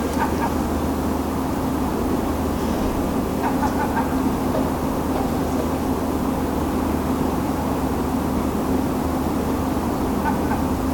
Observação BirdNET - Alcatraz-comum - 2022-04-13 15:43:55
Alcatraz-comum observado com o BirdNET app. 2022-04-13 15:43:55 em Lisboa